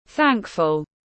Rất biết ơn tiếng anh gọi là thankful, phiên âm tiếng anh đọc là /ˈθæŋk.fəl/
Thankful /ˈθæŋk.fəl/